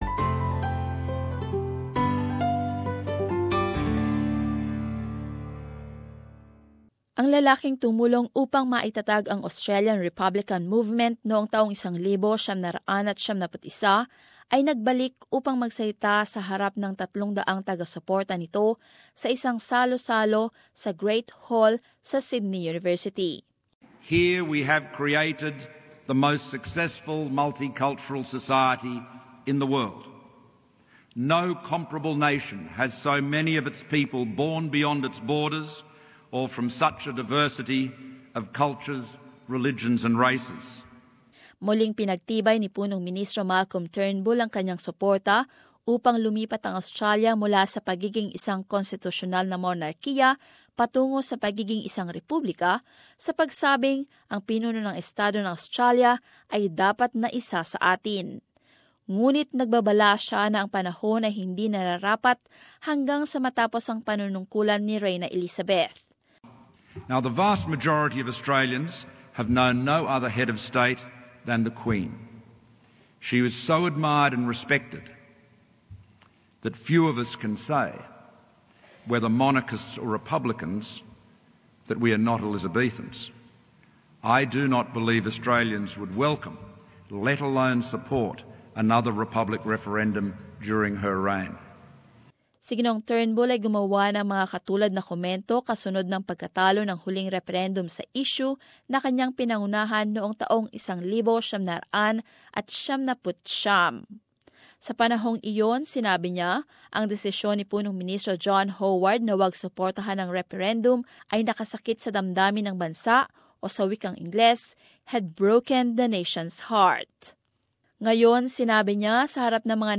Malcolm Turnbull, who was the former chair of the Australian Republican Movement (ARM) made the comment during an address to the organisation's 25th anniversary dinner.